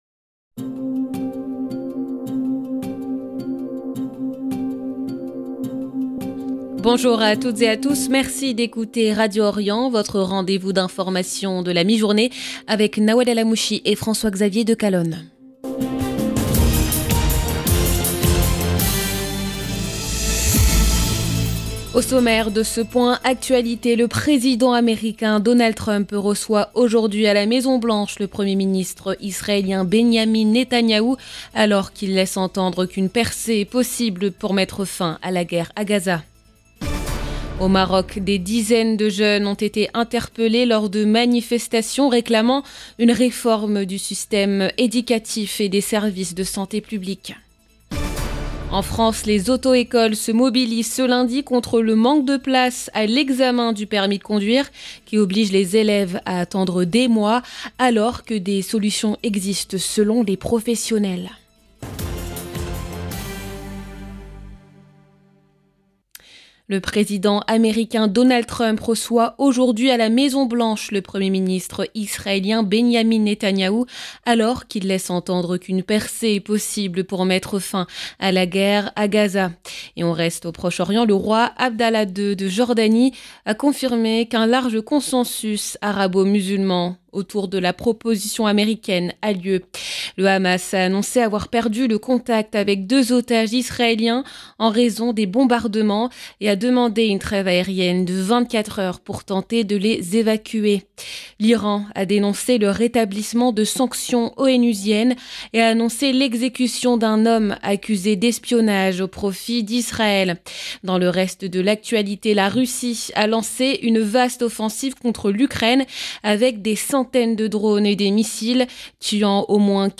Journal de midi du 29 septembre 2025